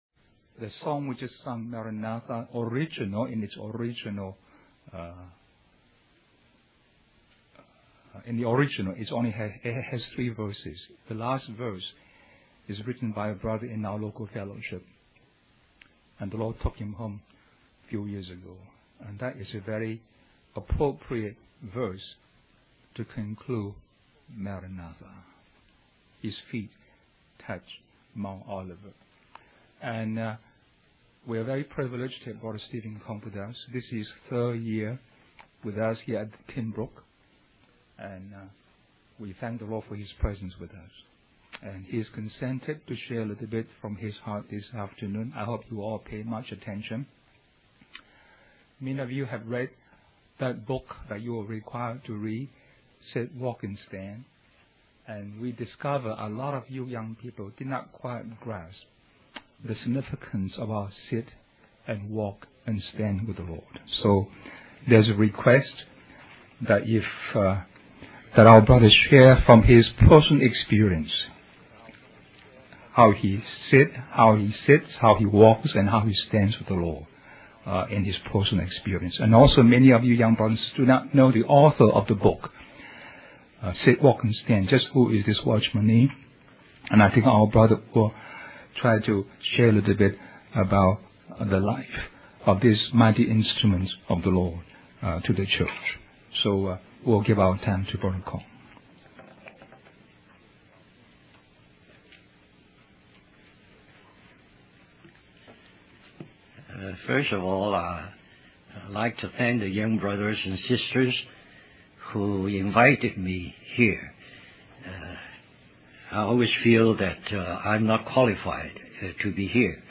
1998 Toronto Summer Youth Conference Stream or download mp3 Summary This message was delivered to the attendees of a youth conference in Toronto Canada.